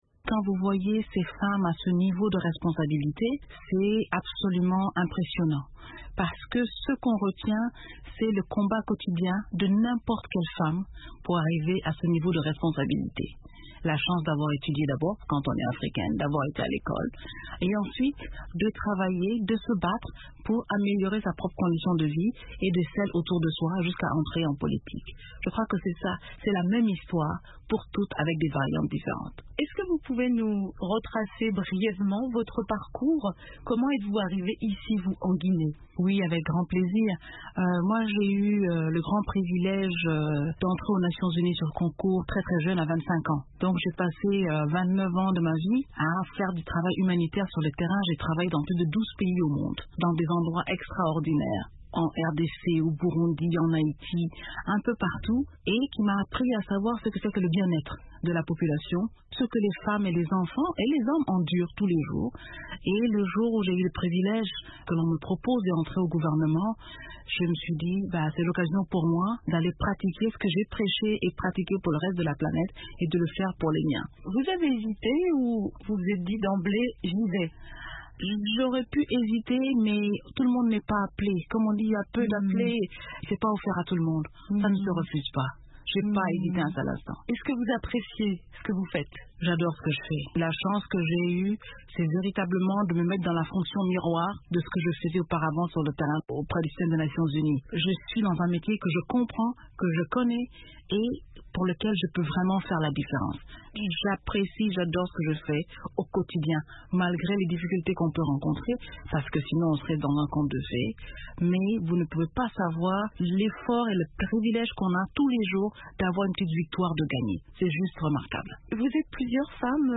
Dr. Diéné Keita, Ministre guinéenne de la Coopération et de l’intégration africaine était de passage dans les locaux de la VOA à Washington, à l’occasion d’un séjour sur invitation de la présidente de l’Assemblée générale des Nations Unies, María Fernanda Espinosa, pour assister à un panel composé de femmes dirigeantes.